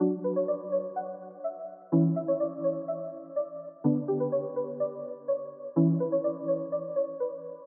环境钟声 125BPM
Tag: 125 bpm Ambient Loops Bells Loops 1.29 MB wav Key : F FL Studio